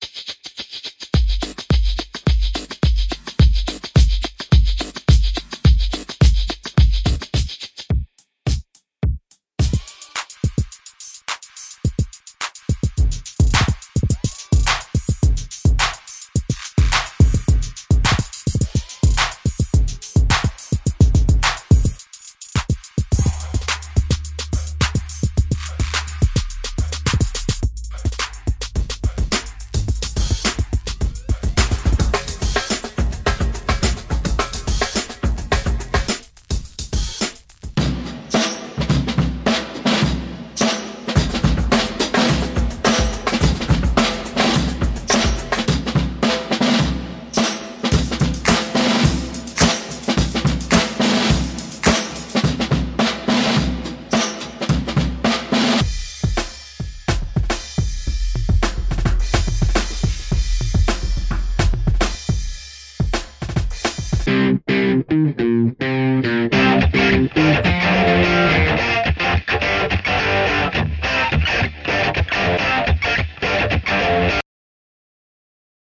Tag: 快乐 漂亮 声音 音乐 免费 歌曲